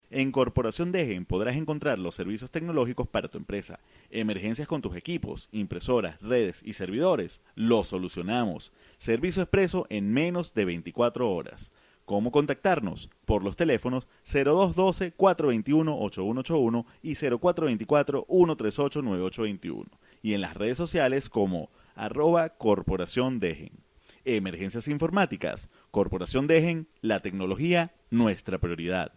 Voz cálida masculina tono medio grave excelente dicción
Sprechprobe: Werbung (Muttersprache):